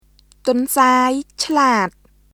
[トンサーイ・チラート　tʷɔ̀nsaːi cʰlaːt]